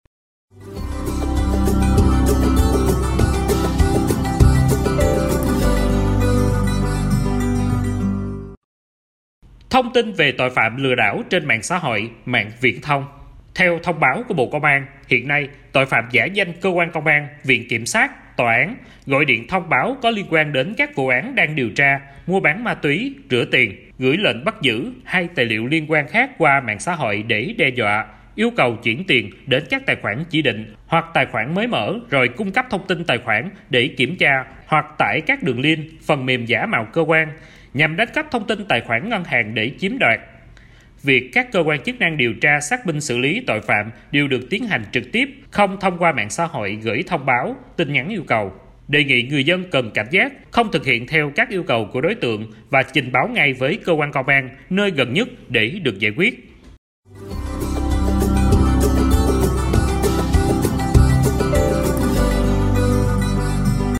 Phát thanh tuyên truyền phòng, ngừa tội phạm lừa đảo trên mạng xã hội, mạng viễn thông